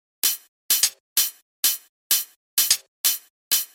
ac 128 bpm house drums 01 hihat
描述：这些循环是在FL Studio 12中创建的，并进行了干式处理。
Tag: 128 bpm House Loops Drum Loops 646.17 KB wav Key : Unknown